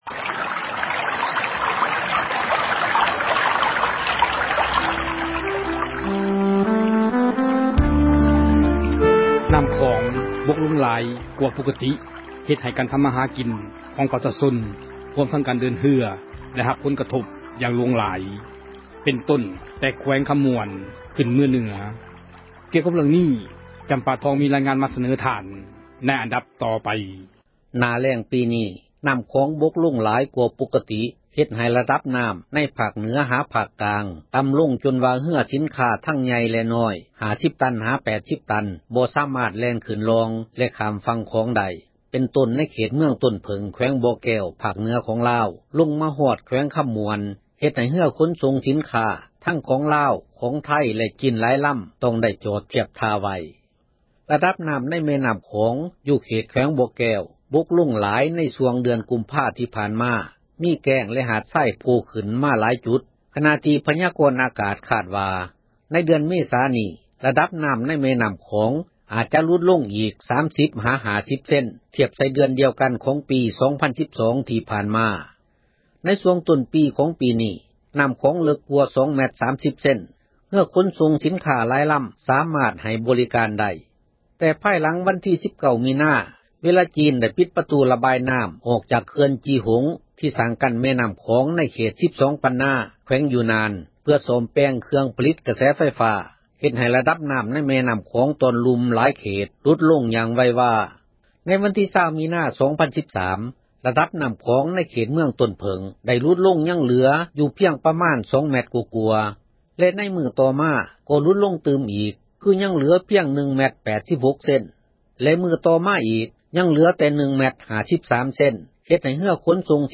ມີຣາຽງານ ກ່ຽວກັບ ເຣື່ອງນີ້ ມາສເນີທ່ານ...